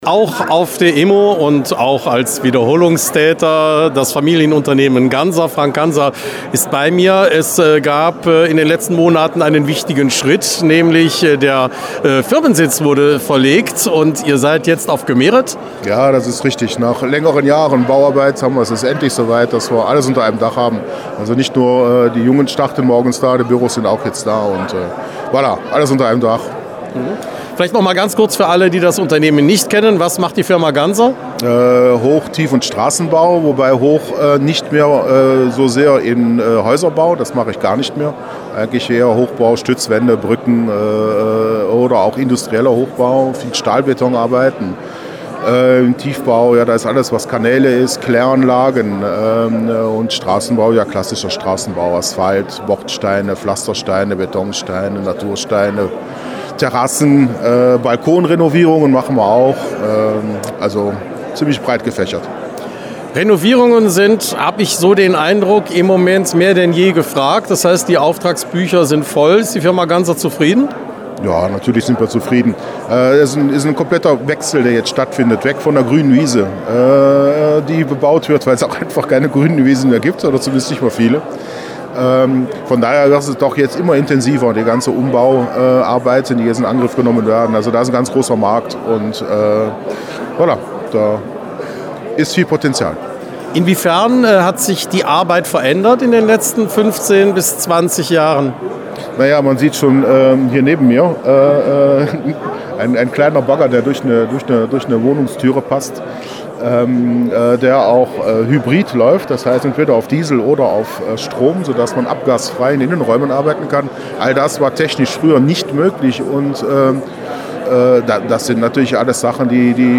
Am 22. und 23. März 2025 findet in der Eastbelgica Eventlocation in Eupen die IMO (Immobilienmesse Ostbelgien) statt.